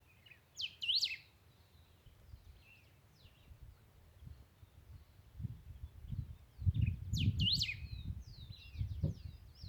Bico-duro (Saltator aurantiirostris)
Nome em Inglês: Golden-billed Saltator
Detalhada localização: Aldea San Gregorio
Condição: Selvagem
Certeza: Observado, Gravado Vocal
Pepitero-de-collar-1_1.mp3